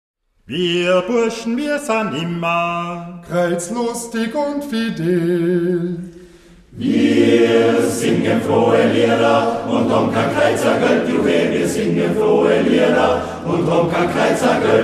Aufgenommen im Oktober 2009 in der Volksschule
Volksweise